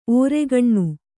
♪ ōregaṇṇu